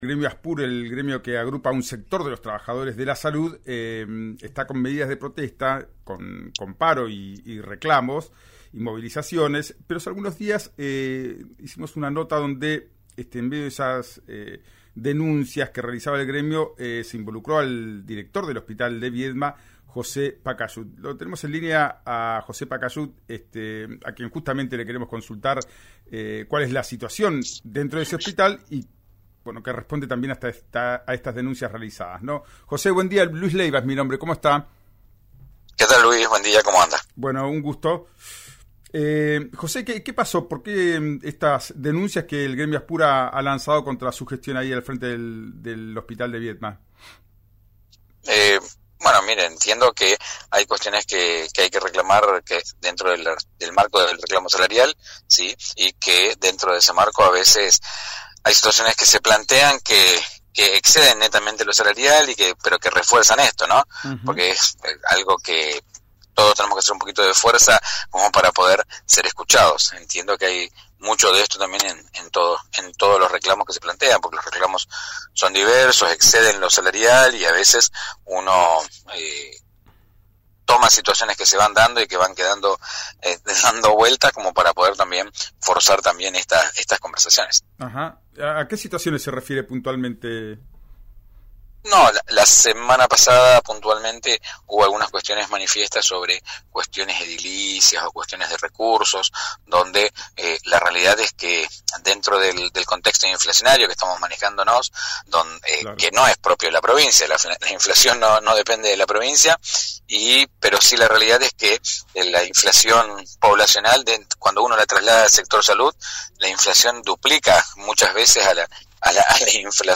En diálogo con RÍO NEGRO RADIO recordó que «la pasada semana hubo cuestiones manifiestas sobre cuestiones edilicias o de recursos», explicó que «dentro del contexto inflacionario, que no es propio de la provincia, pero cuando se traslada al sector salud se duplica, nos manejamos en recursos que son en dólares, que también sufren aumentos» y agregó que «en las compras descentralizadas desde el hospital a veces hay atrasos en las entregas» porque son suministros «que no están en el país».